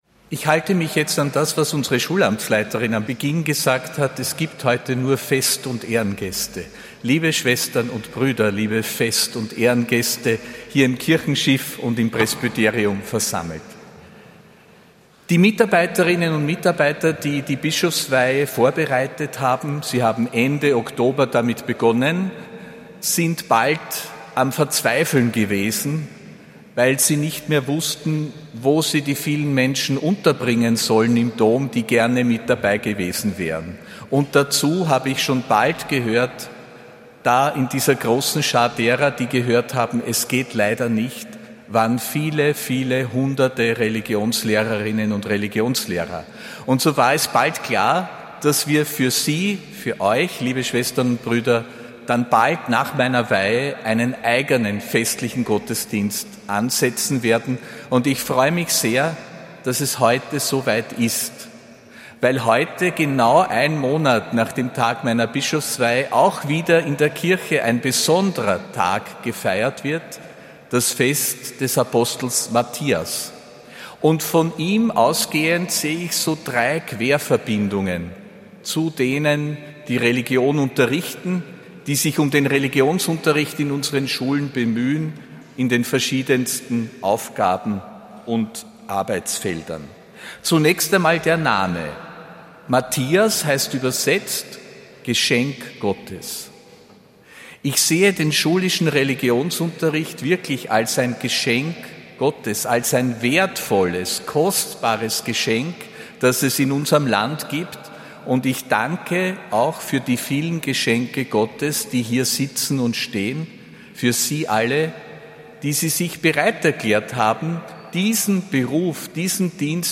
Predigt von Erzbischof Josef Grünwidl bei der Bischofsmesse für Religionsleherinnen und Religionslehrer, am 24. Februar 2026.